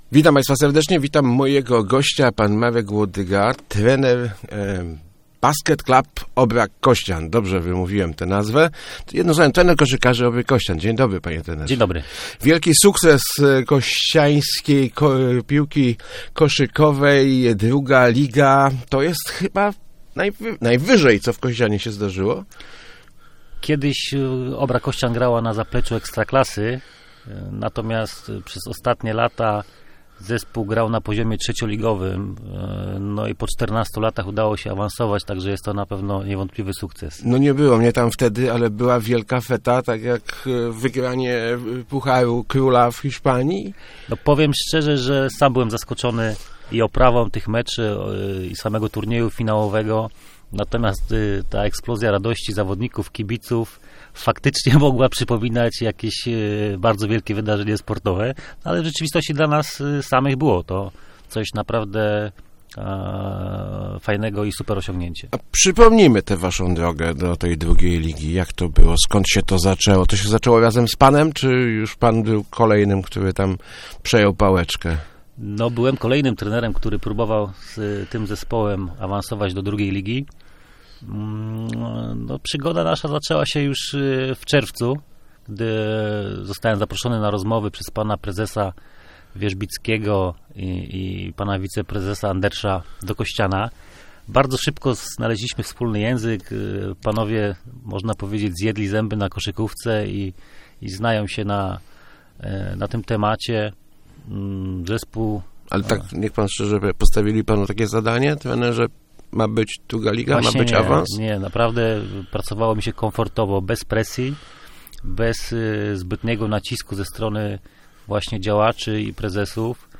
Start arrow Rozmowy Elki arrow Wielki awans Obry Kościan